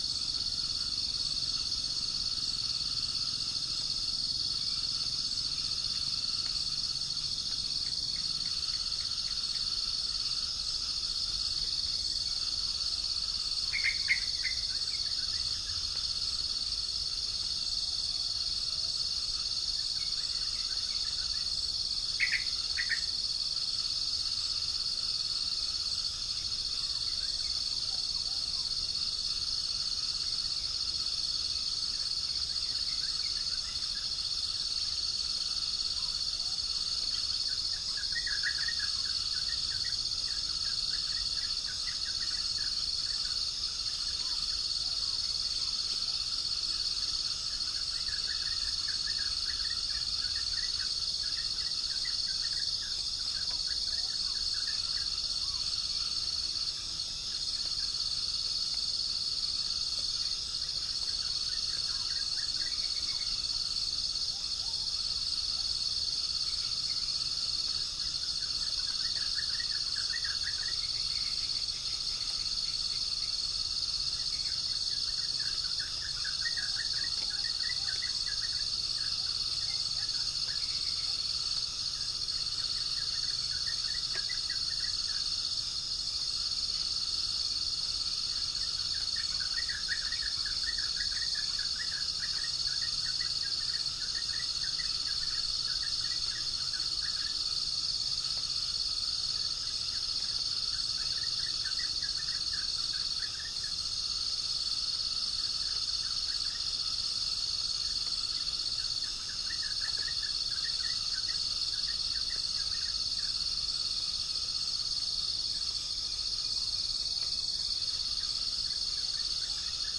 Gallus gallus
Centropus bengalensis
Pycnonotus goiavier
Prinia familiaris